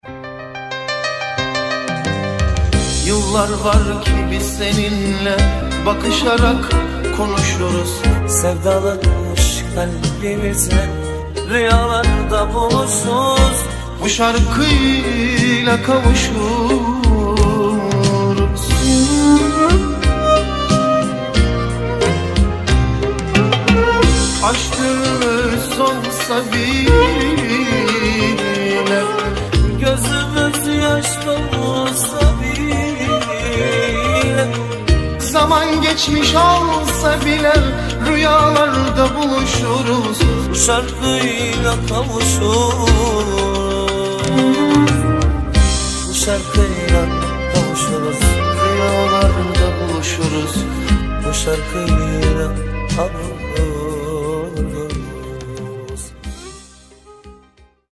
Kemençeçi